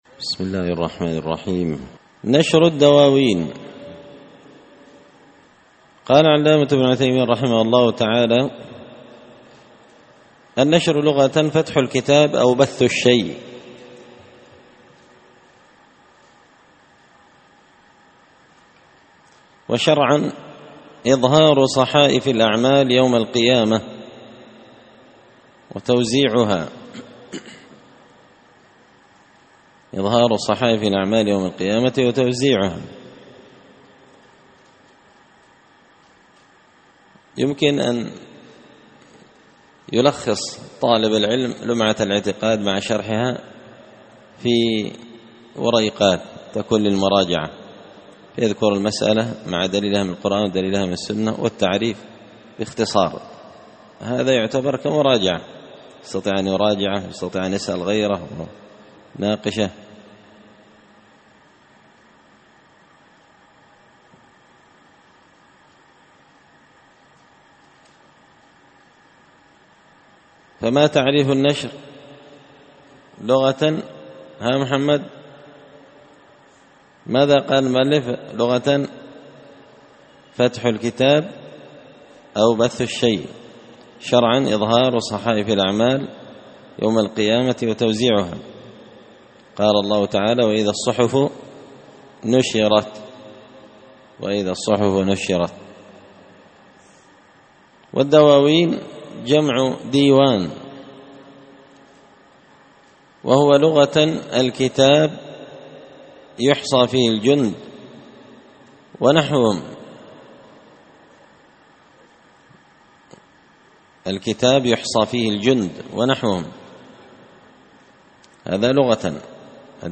شرح لمعة الاعتقاد ـ الدرس 39
دار الحديث بمسجد الفرقان ـ قشن ـ المهرة ـ اليمن